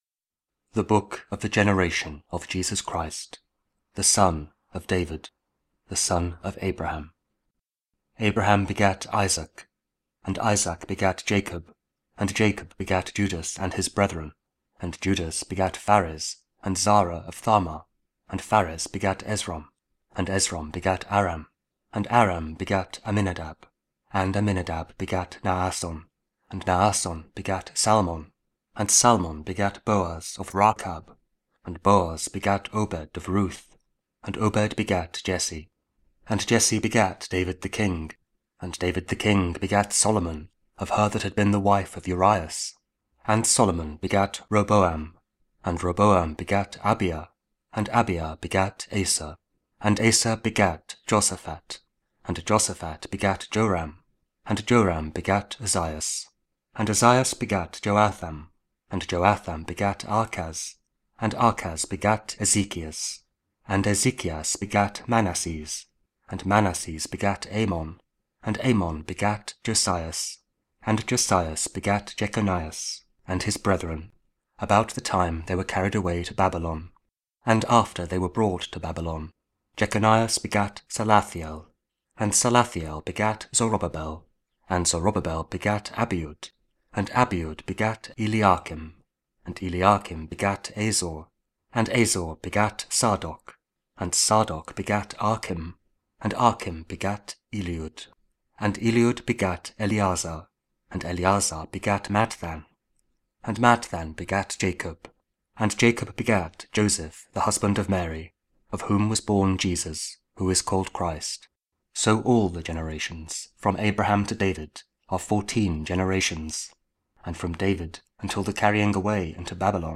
Matthew 1: 1-17 | 17th December Advent Weekday (Audio Bible, Spoken Word)